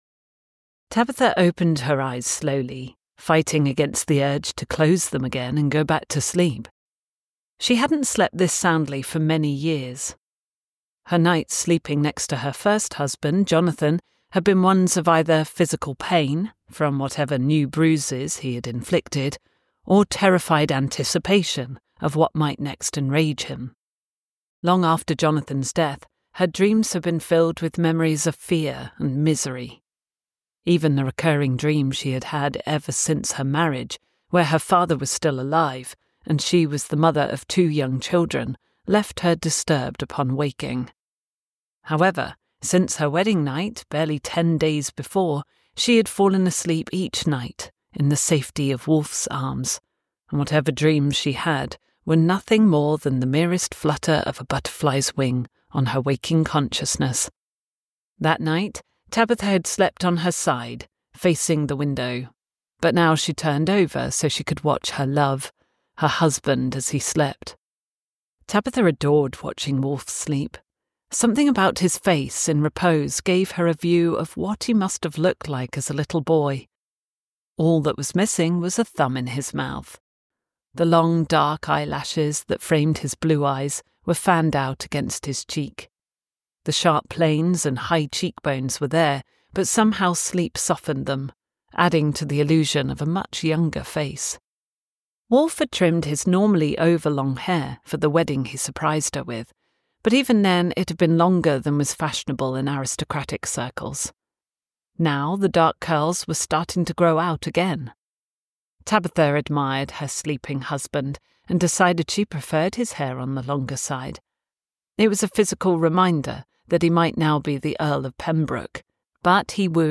An Intrepid Woman Audiobook